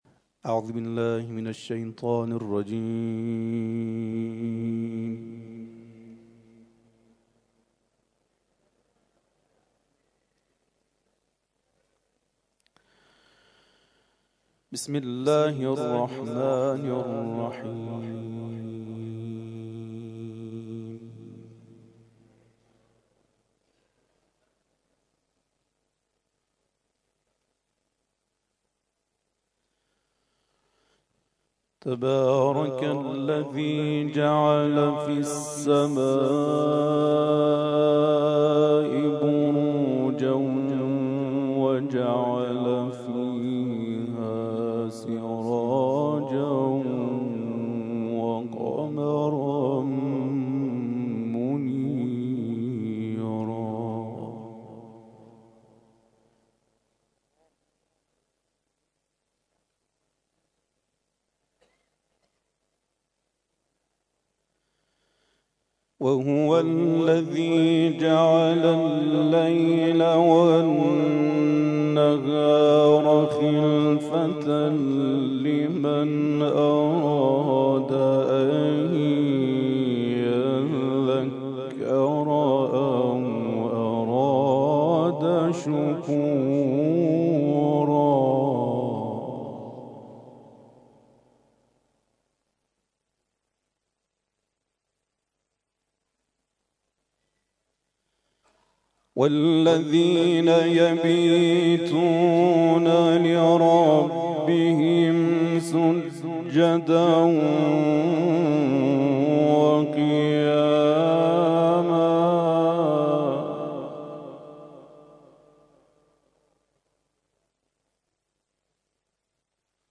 گروه جلسات و محافل: کرسی‌های تلاوت نفحات القرآن